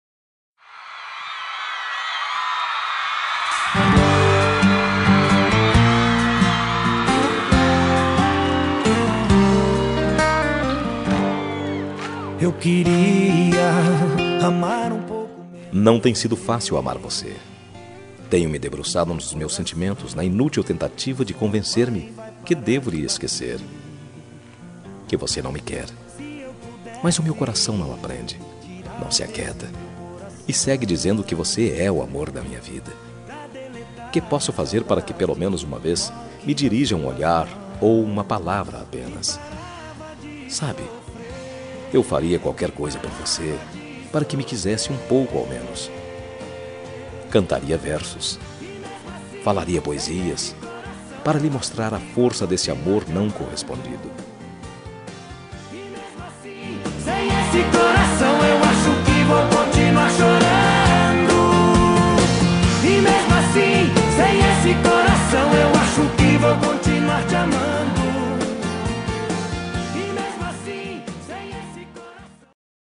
Telemensagem Amor Não Correspondido – Voz Masculina – Cód: 7963 – Linda